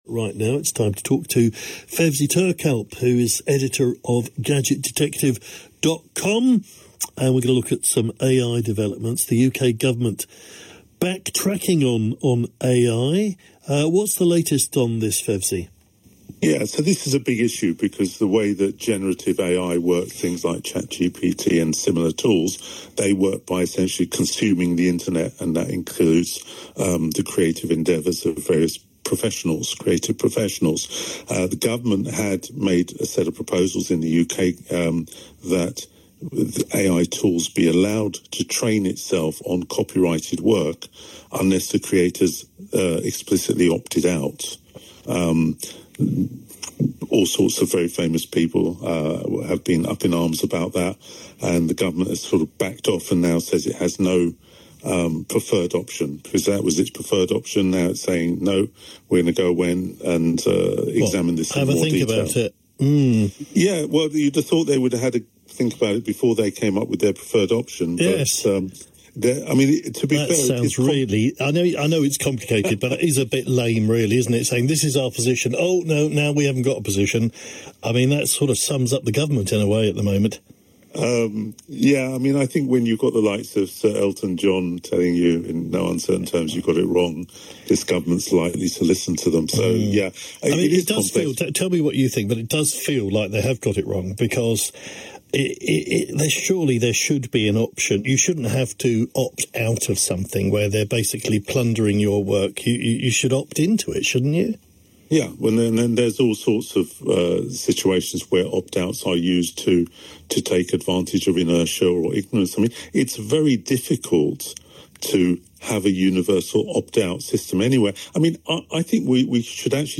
tech news broadcasts